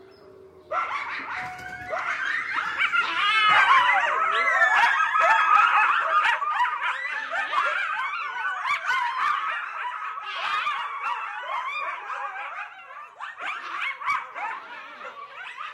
The range of sounds that they make, high pitched and varied, are to call the group together and to communicate their position.
kids-coyoteaudio.mp3